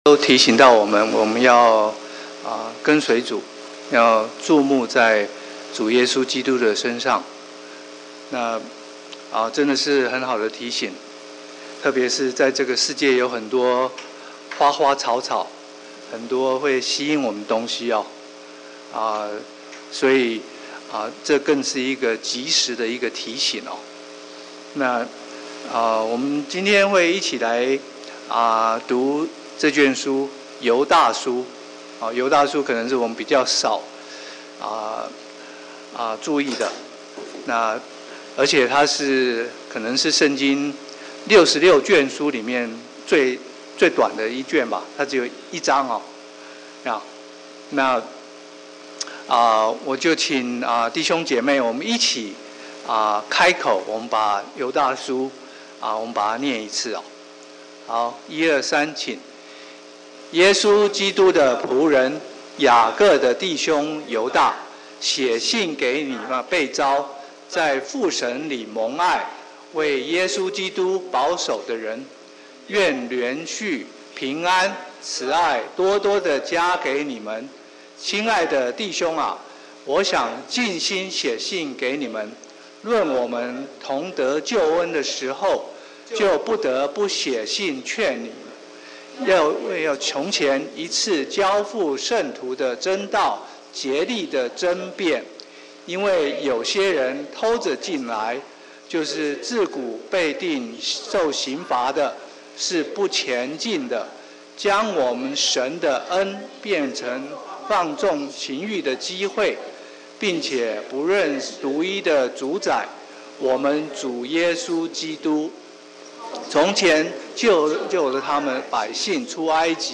周五晚上查经讲道录音